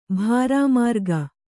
♪ bhārāmārga